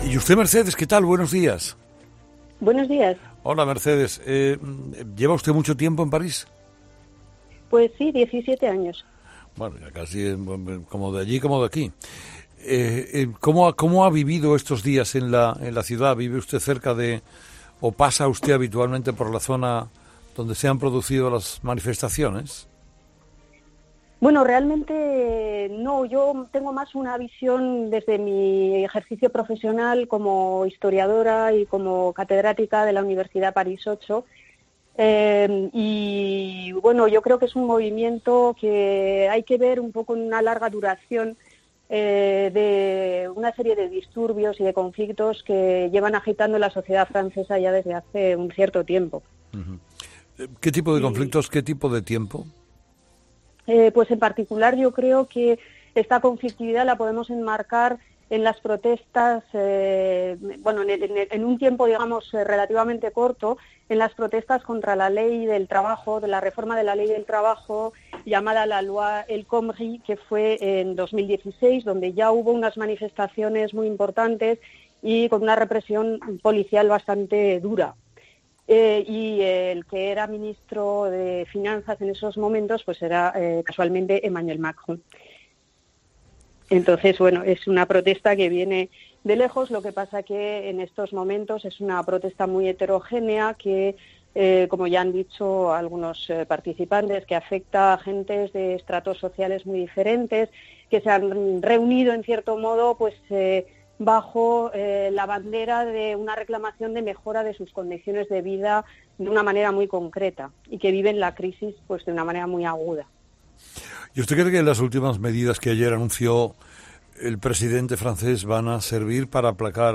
Este martes los oyentes de Herrera afincados en Francia han relatado sus impresiones sobre este movimiento